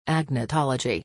PRONUNCIATION:
(ag-nuh-TOL-uh-jee)